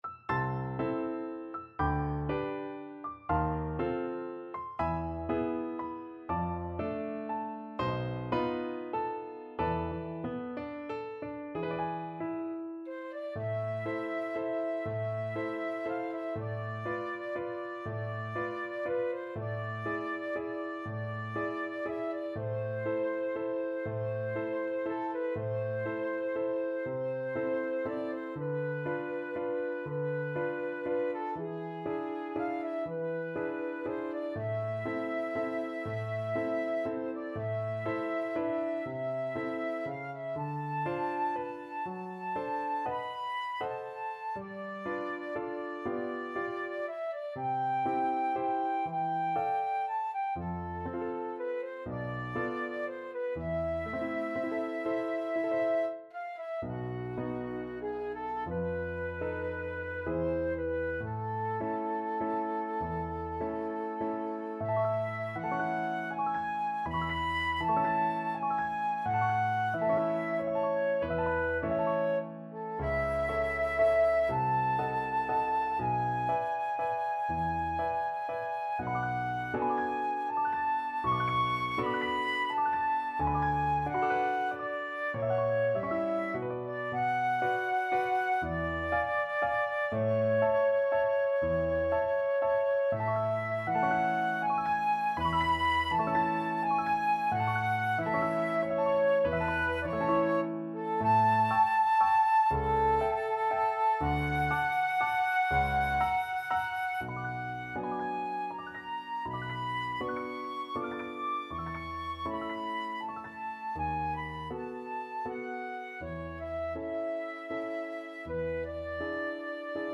Flute version
3/4 (View more 3/4 Music)
~ = 120 Lento
Classical (View more Classical Flute Music)